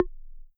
menuclick.wav